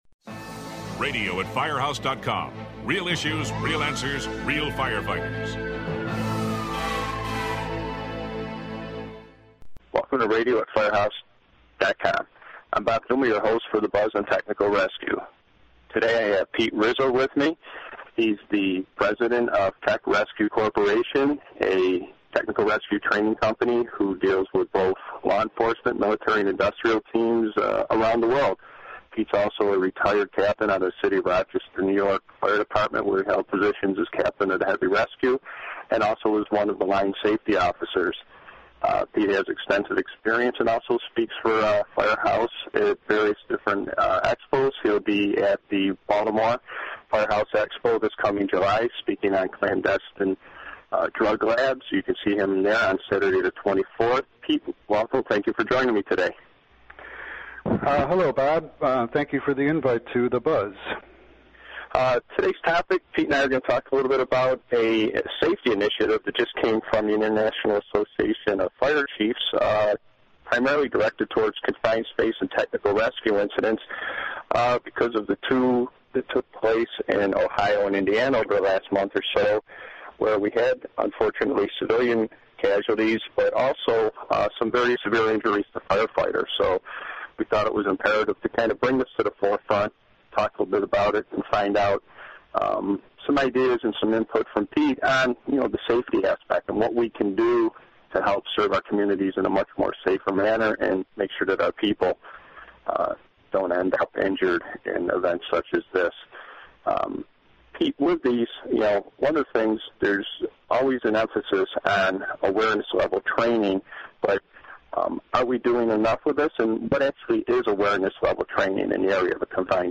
The two discuss a wide variety of concerns that all responders consider before attempting a rescue, especially at confined space incidents.